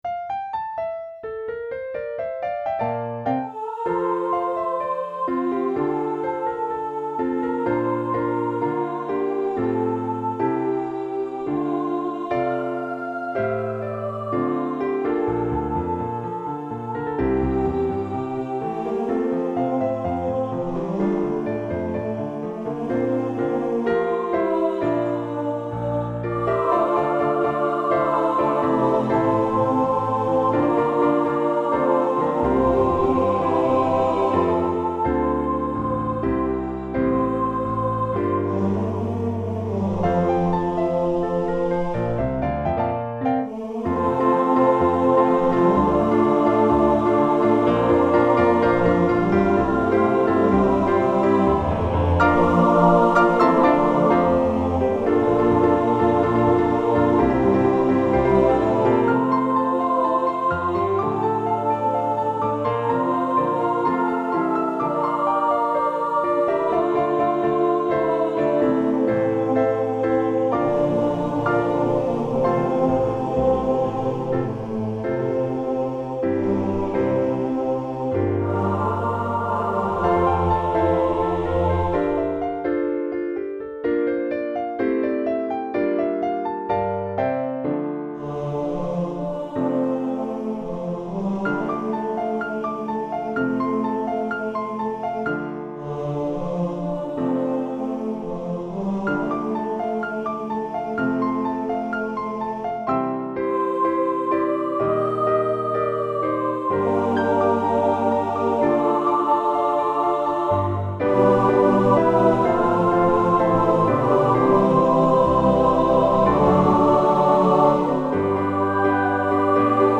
For Choir and Piano